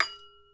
REDD PERC (22).wav